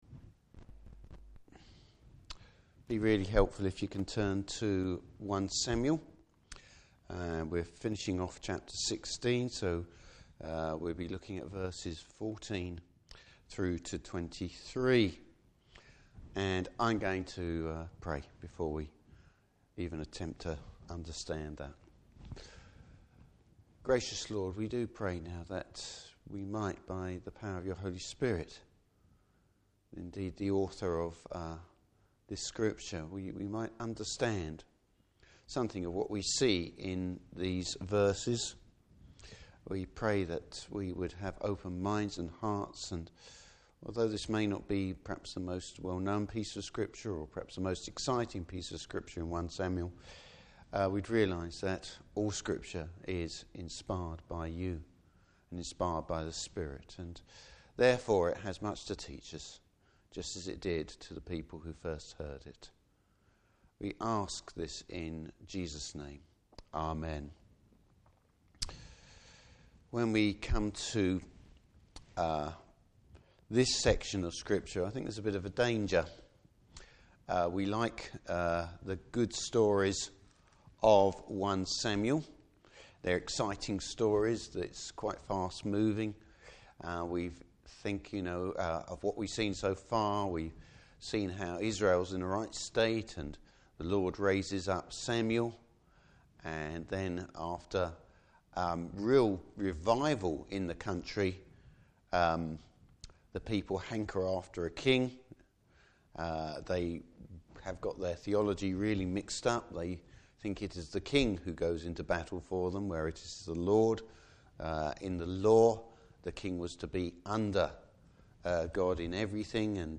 Service Type: Evening Service How the Lord’s providence puts David in Saul’s Court.